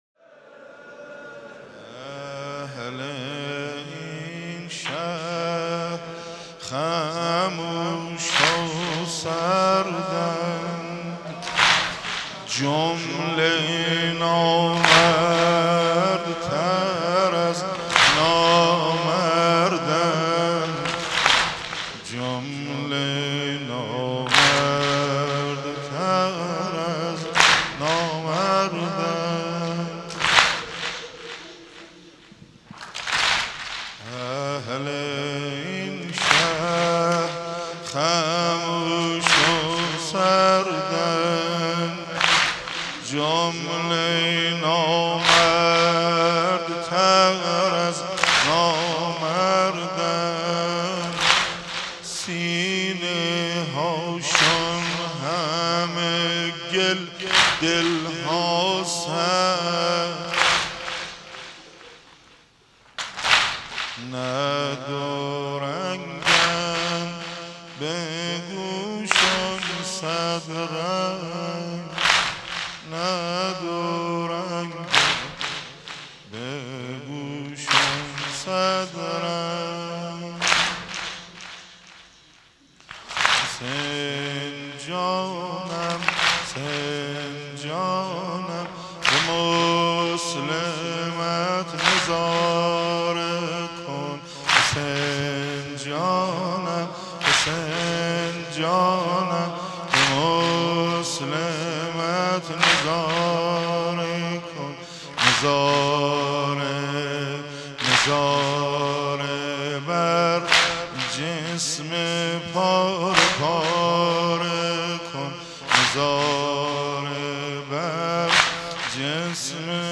عقیق: مراسم شب اول محرم در مسجد امیر(ع) برگزار شد.
صوت مراسم